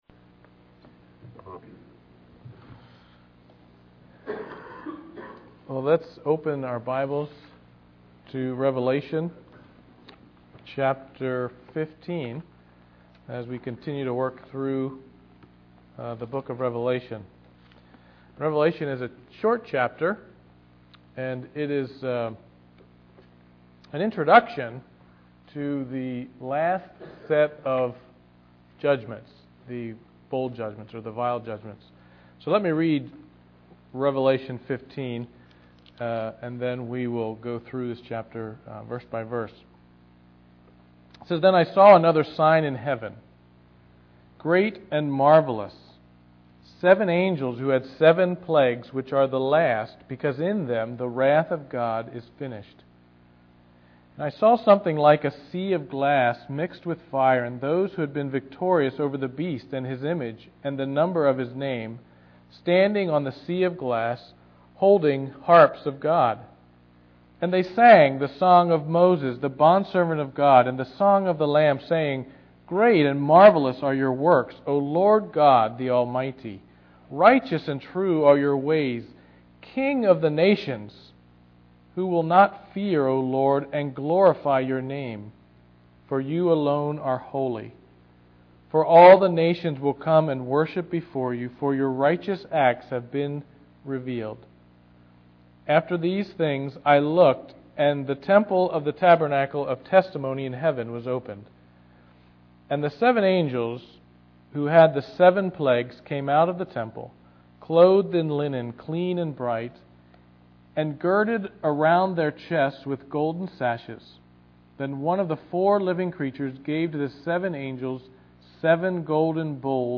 Passage: Revelation 15 Service Type: Sunday Evening Worship